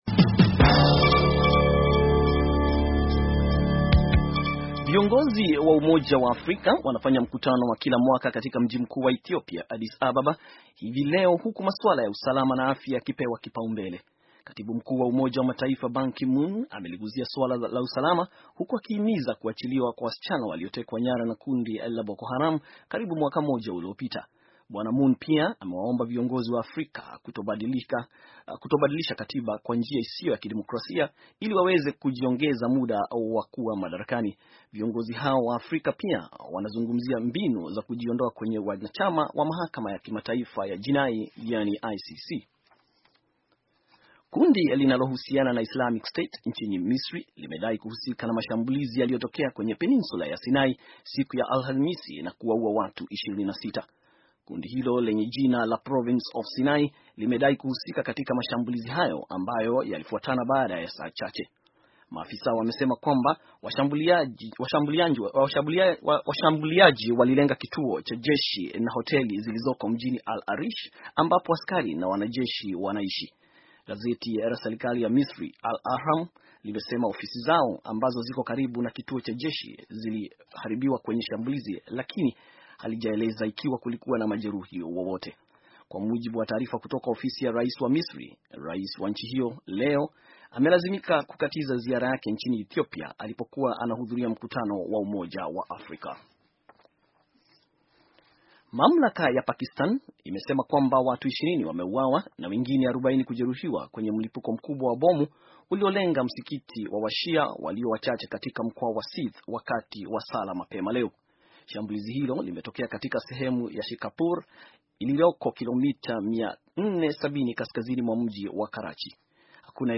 Taarifa ya habari - 4:30